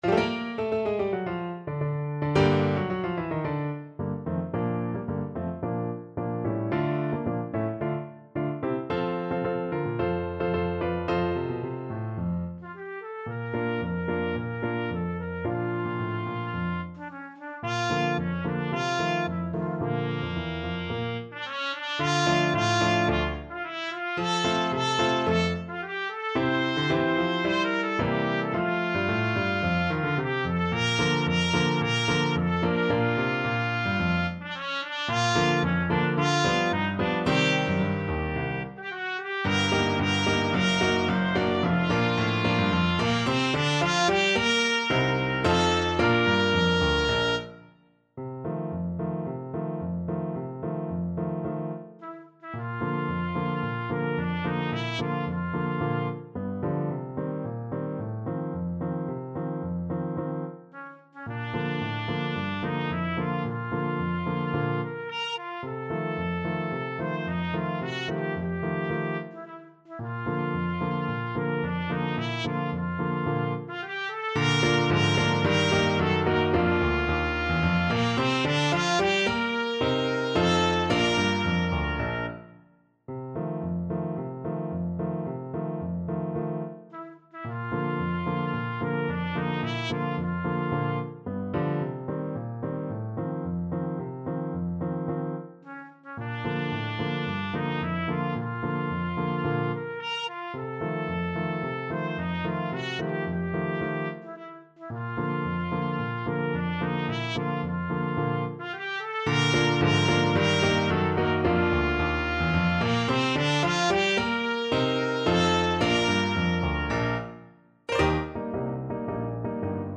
Classical (View more Classical Trumpet-Trombone Duet Music)